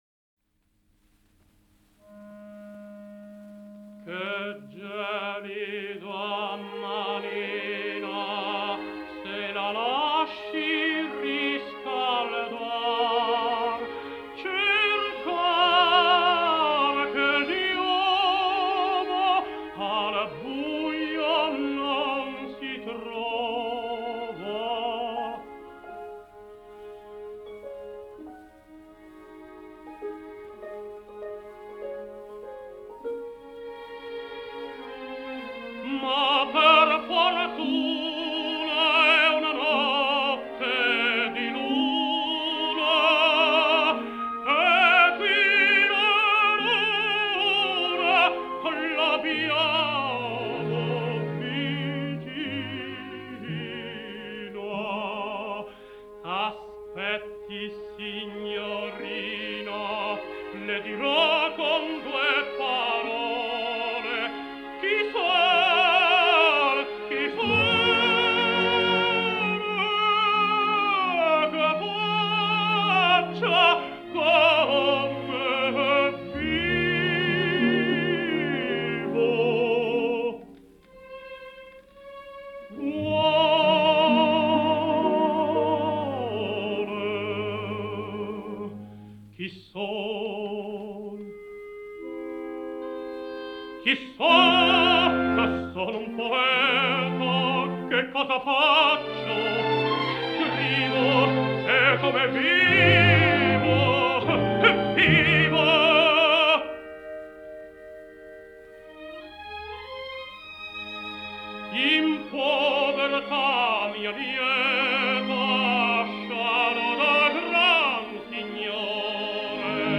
Жанр: Opera